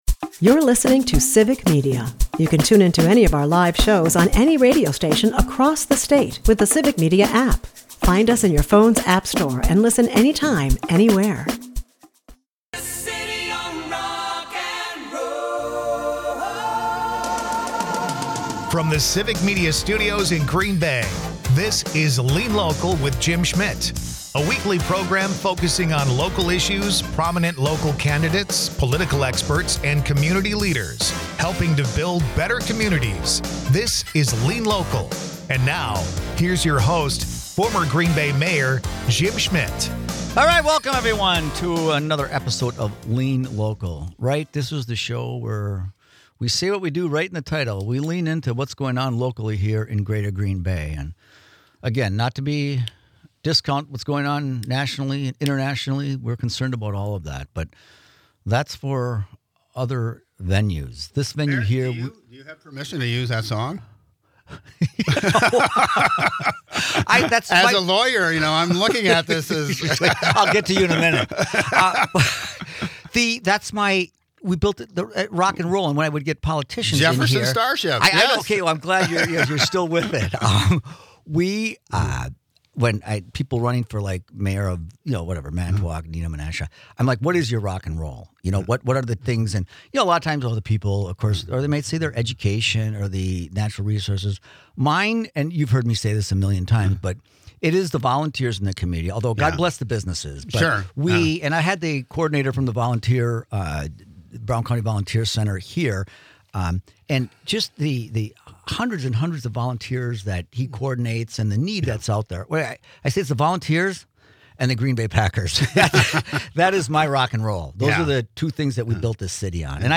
This week on Lean Local, Jim Schmitt sits down with Mark Murphy, President and CEO of the Green Bay Packers, for a conversation that bridges football, leadership, and community.